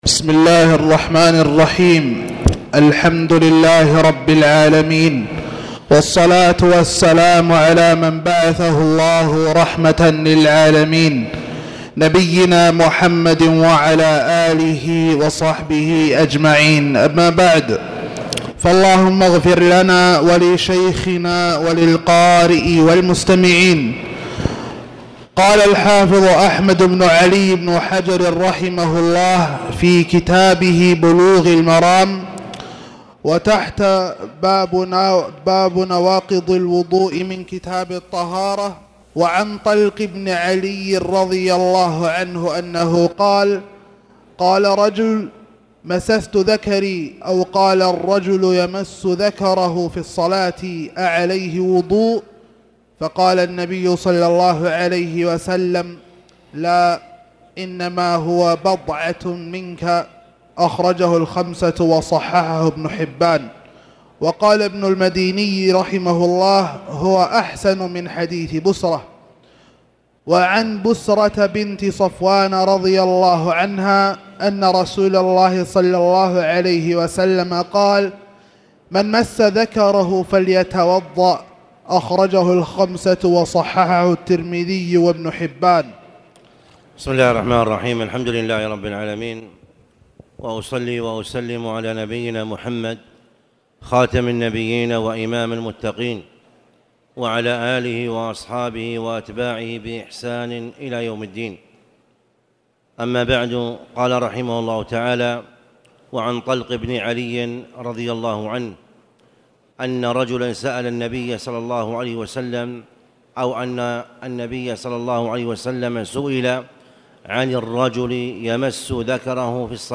تاريخ النشر ٢٠ ربيع الثاني ١٤٣٨ هـ المكان: المسجد الحرام الشيخ: خالد بن عبدالله المصلح خالد بن عبدالله المصلح كتاب الطهارة-نواقض الوضوء